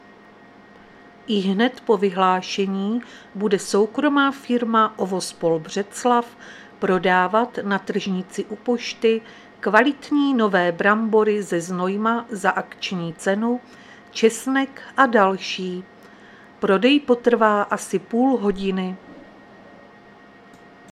Záznam hlášení místního rozhlasu 12.6.2025
Zařazení: Rozhlas